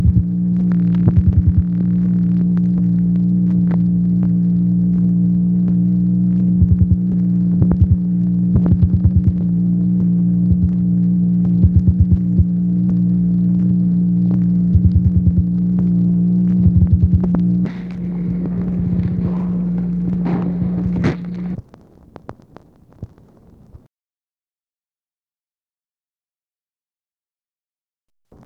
OFFICE NOISE, July 10, 1964
Secret White House Tapes | Lyndon B. Johnson Presidency